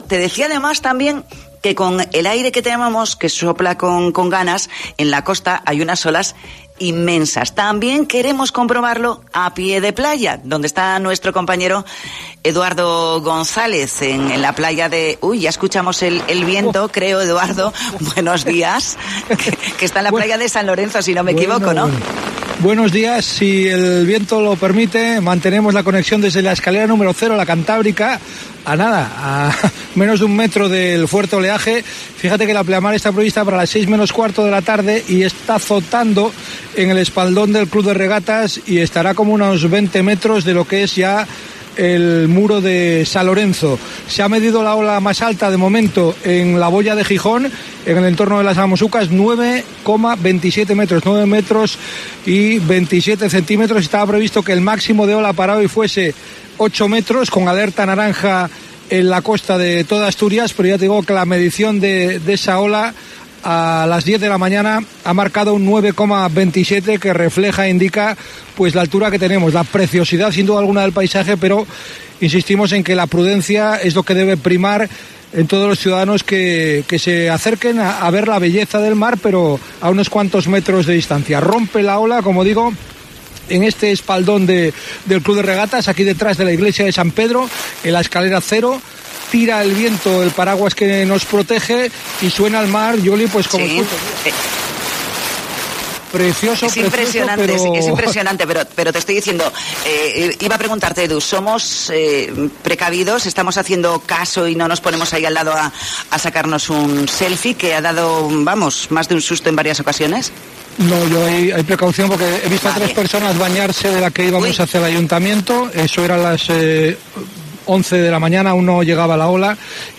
Así te contamos en COPE Asturias la intensidad del oleaje en la bahía de Gijón